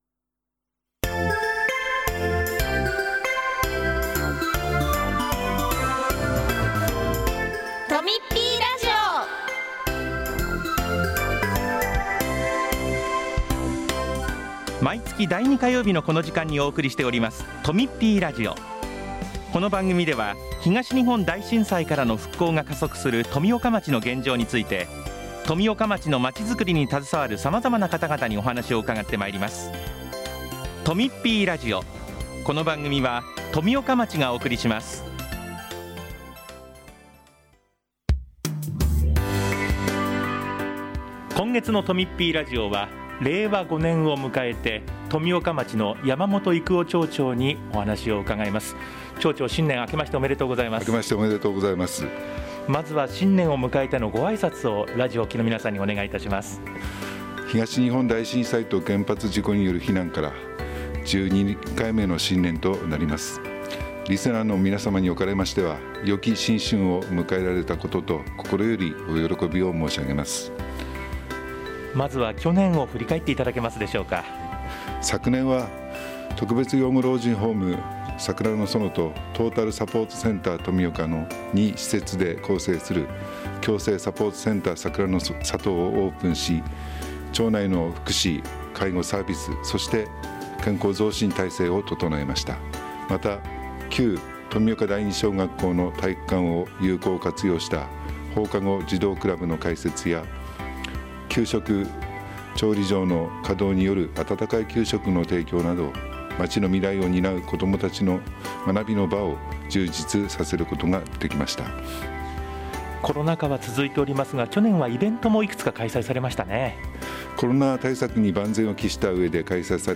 1月10日（火曜日）に放送した「とみっぴーラジオ」を、お聴きいただけます。
今回は、山本育男町長が、2022年を振り返るとともに、2023年の新たな動きなどにお話しします。その他、町からのお知らせもあります。